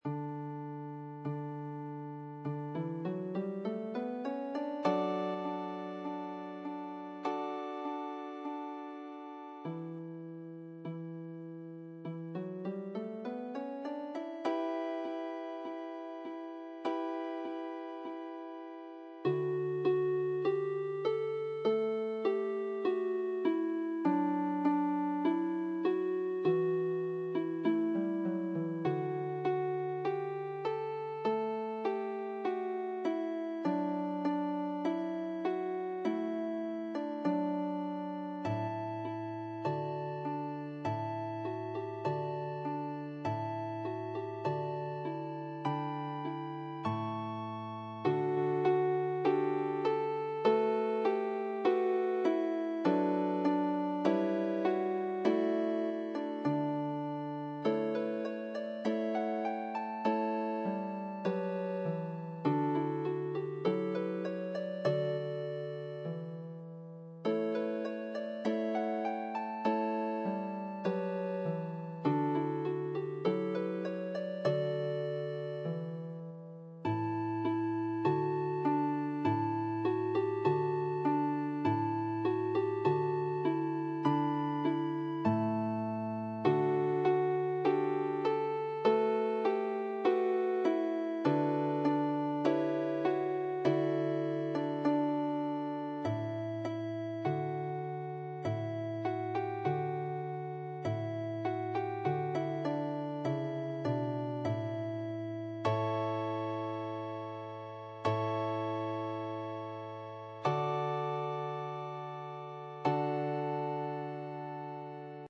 3 or more lever or pedal harps with optional strings
Key of D, no lever changes.